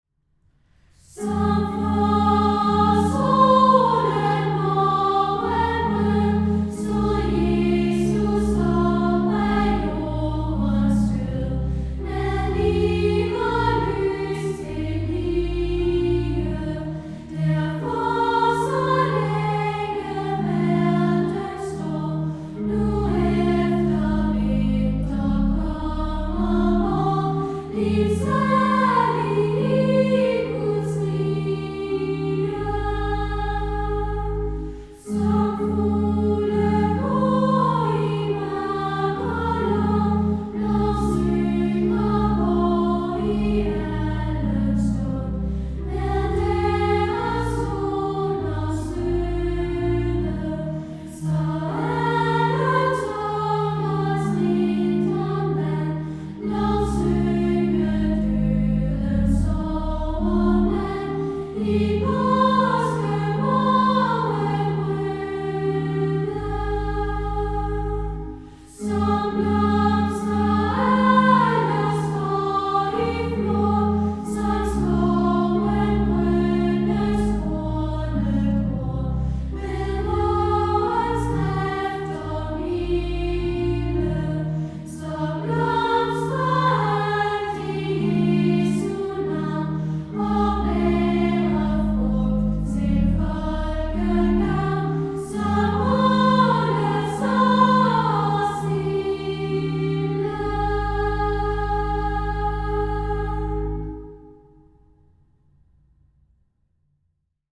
Også Grundtvig bruger solopgangen som billede på Jesus' opstandelse i salmen Som forårssolen morgenrød (Salmebogen nr 234).